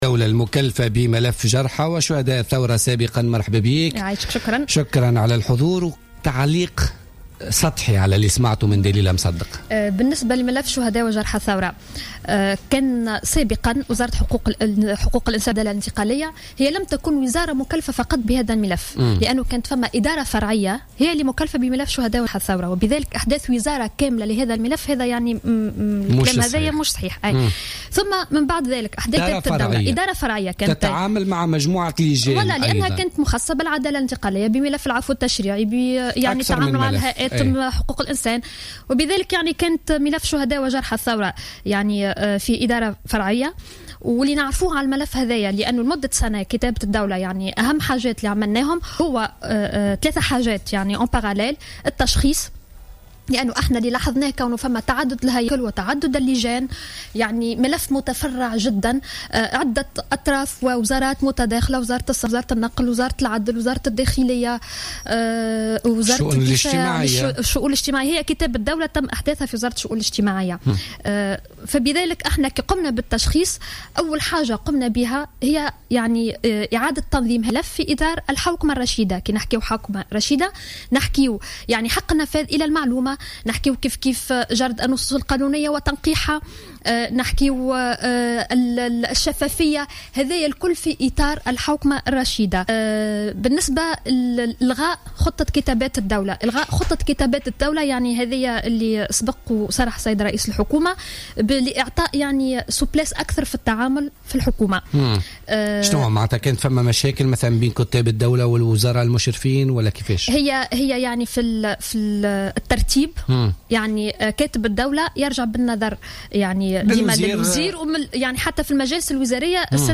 أكدت كاتبة الدولة المكلفة بملف شهداء وجرحى الثورة السابقة ماجدولين الشارني ضيفة بوليتيكا اليوم الخميس 4 فيفري 2016 أن التخلي عنها ككاتبة دولة لا يعني غلق ملف شهداء وجرحى الثورة موضحة أنه سيتم احداث هياكل جديدة تعنى بملف شهداء وجرحى الثورة وتتابع الأعمال التي كانت قد قامت بها من منصبها.